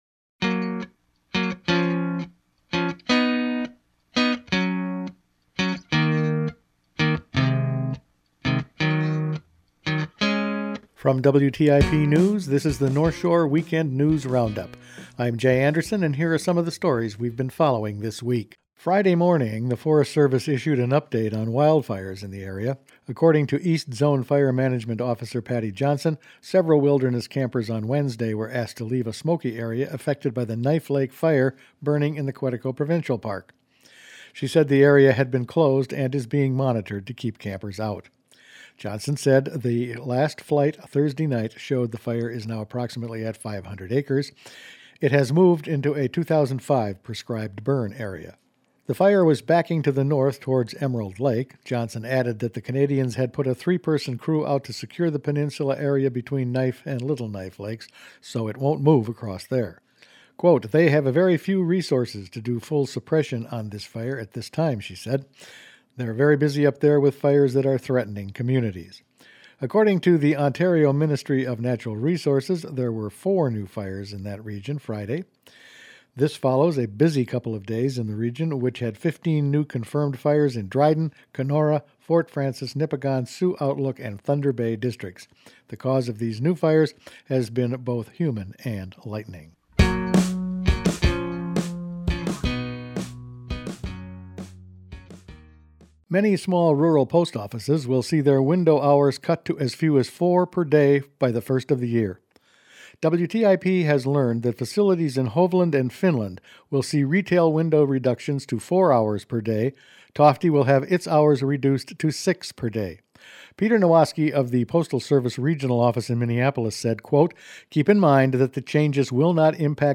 Weekend News Roundup for September 15